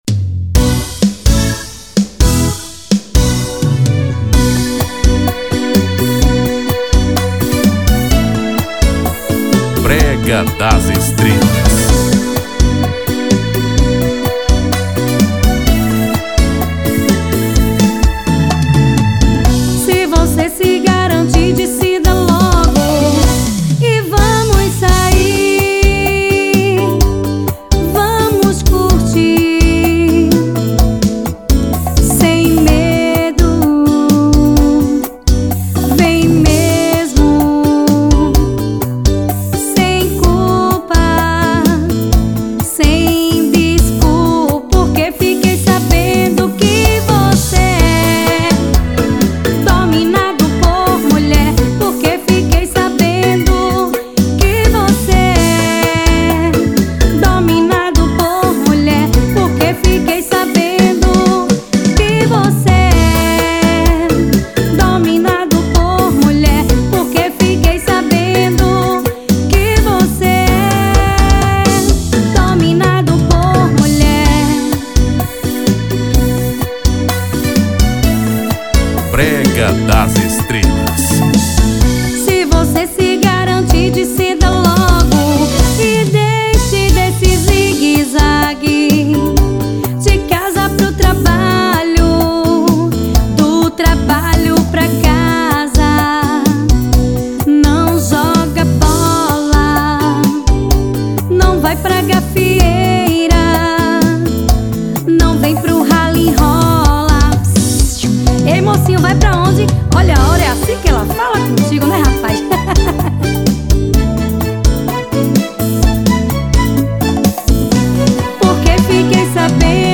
EstiloBrega